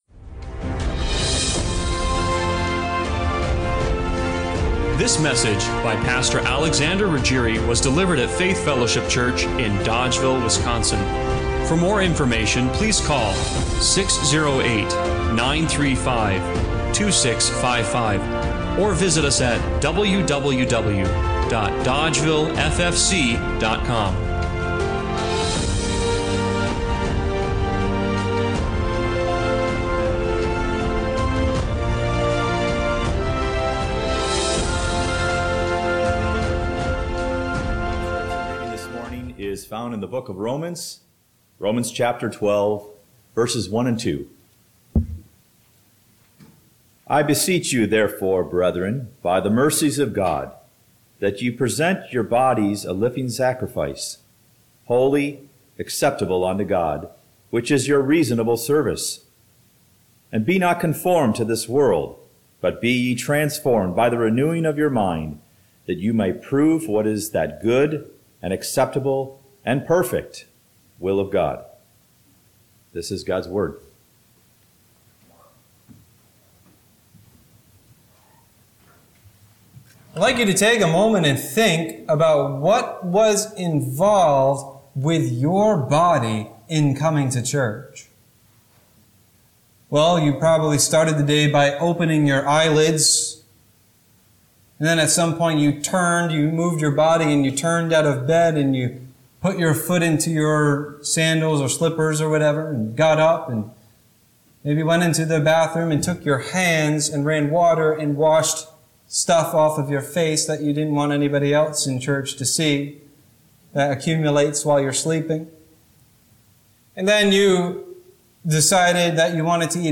Romans 12:1-2 Service Type: Sunday Morning Worship What if your body wasn’t just yours to manage—but God’s to move?